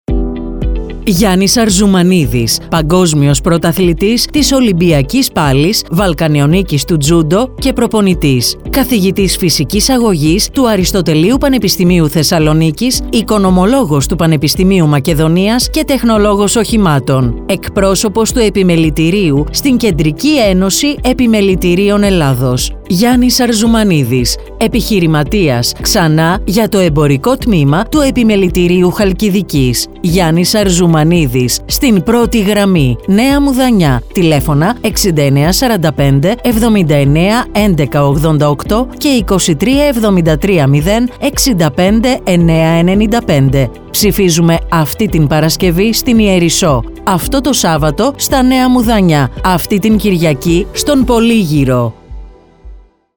ΗΧΗΤΙΚΑ  ΕΝΗΜΕΡΩΤΙΚΑ ΜΗΝΥΜΑΤΑ